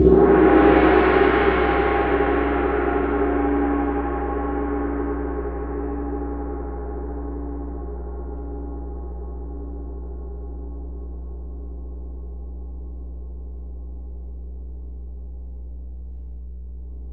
CYM TAM-T0AL.wav